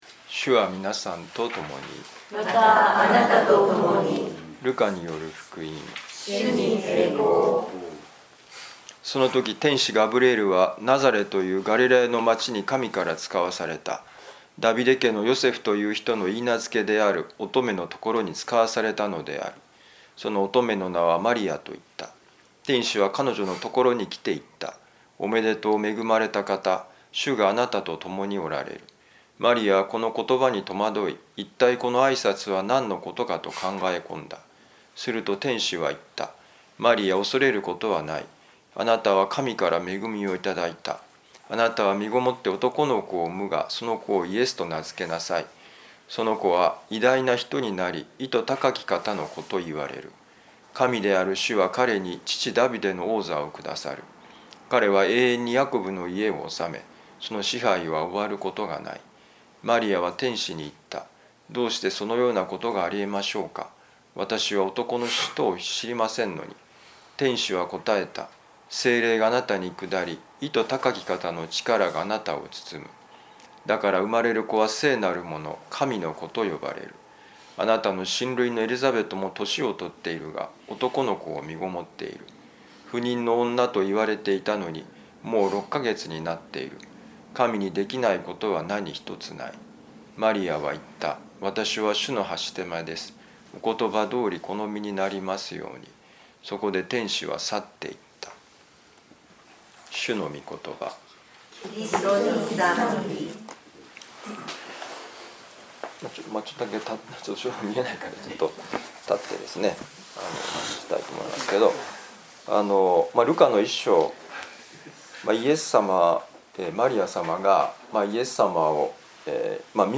【ミサ説教】
ルカ福音書1章26-38節「どうしてこのようなことが、と思うとき」2025年3月24日いやしのミサ旅路の里